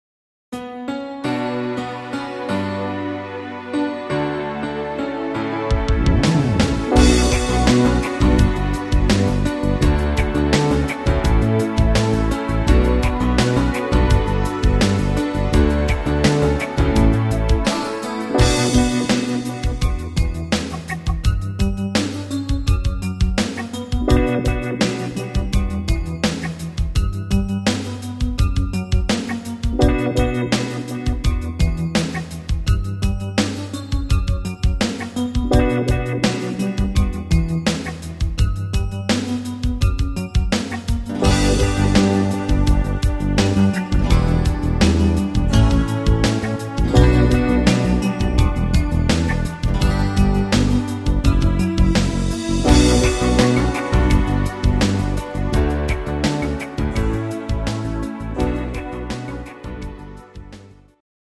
Rhythmus  Medium Pop
Art  Englisch, Pop, Weibliche Interpreten